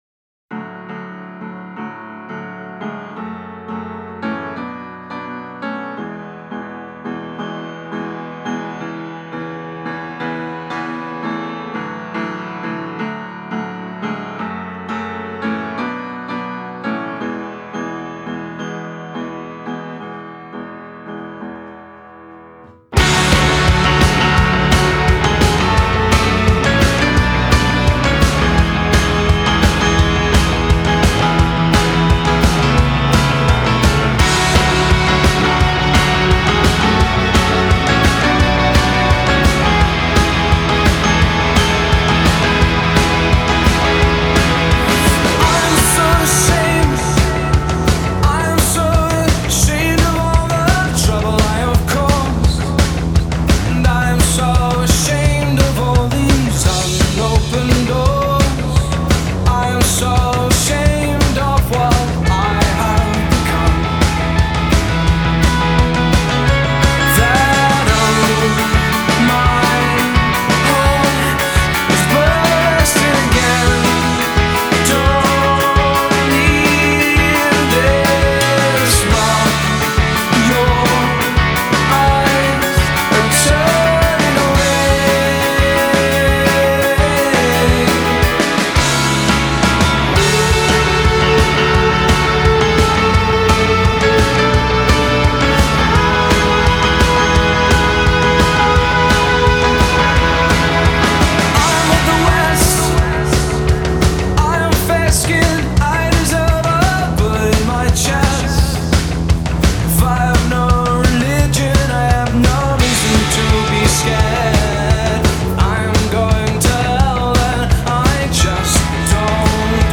pop rockers
is fast paced with bright piano layered over top of guitars